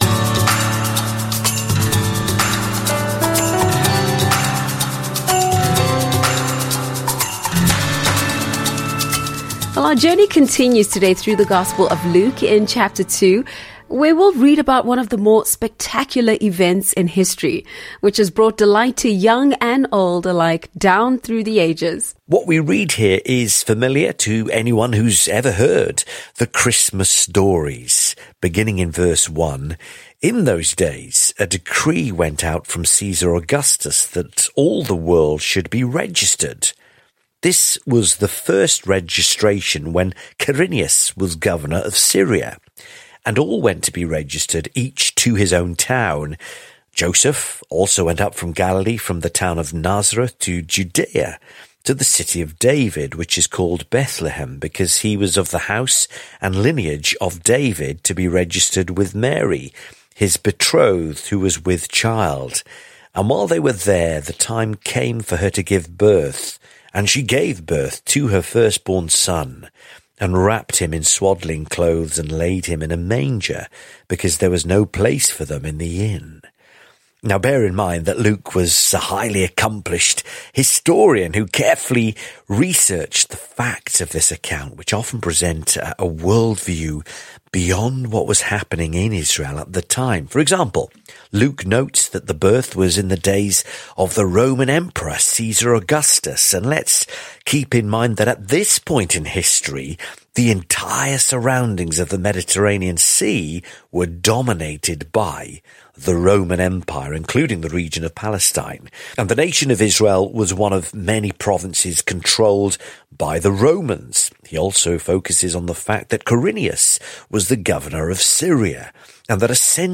This episode tells the story of Jesus' birth in Bethlehem, highlighting the events surrounding his arrival. Listen to this excerpt of the Mission 66 lesson on Luke, chapter 2